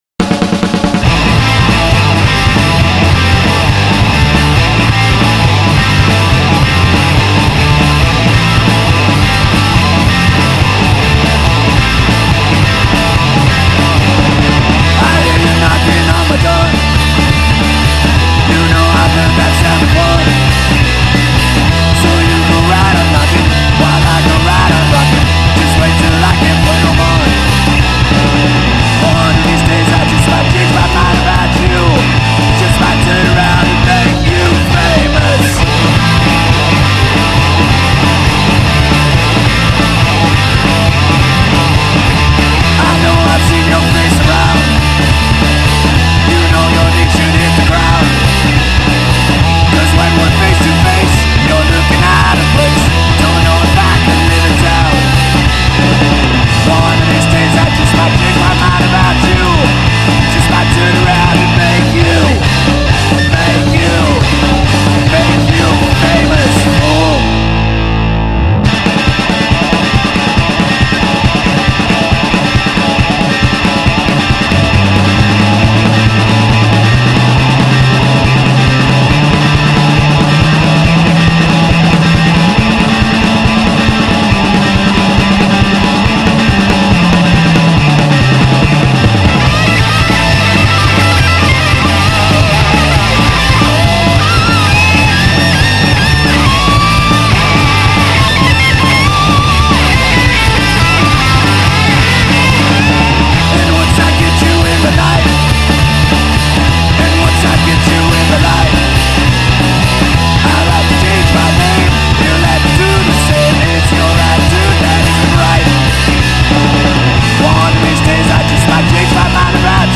Chicago's Premiere Heavy Metal Rockers